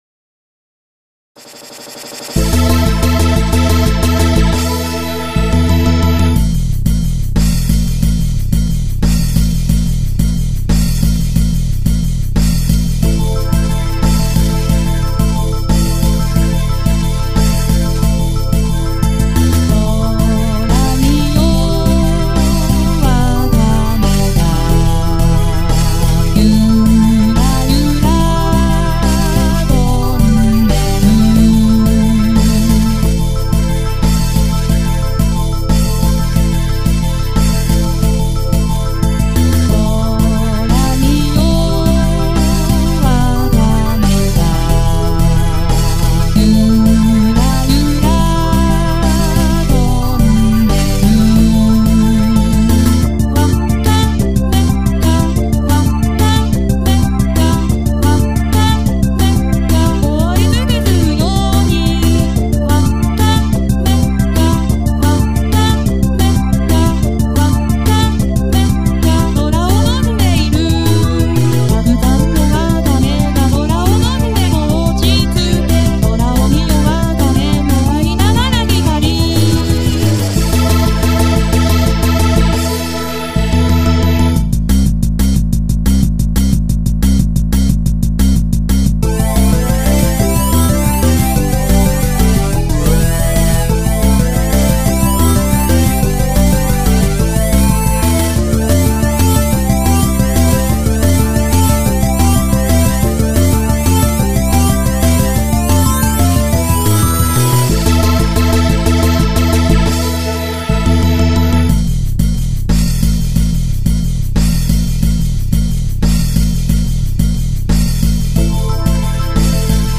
歌付き) 上記の曲に歌を付けてみた。伴奏にAlbino3等のソフトシンセを使用。